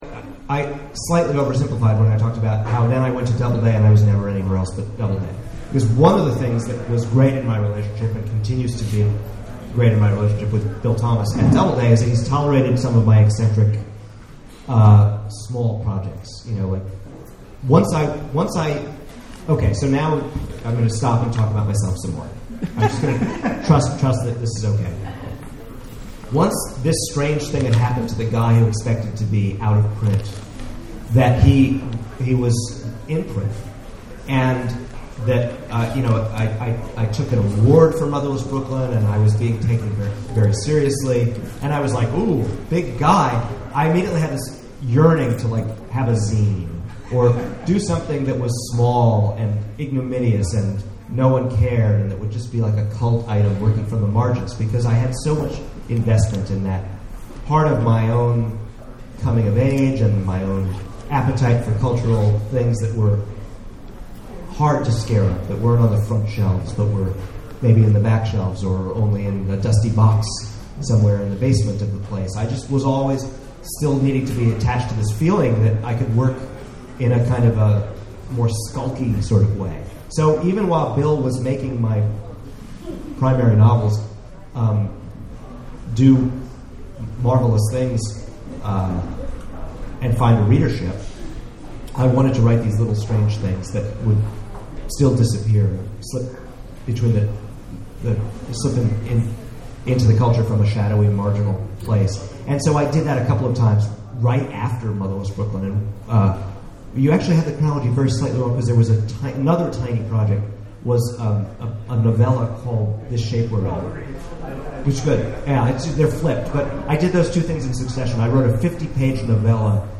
at The Last Bookstore in downtown Los Angeles for an in-depth discussion of his book covers.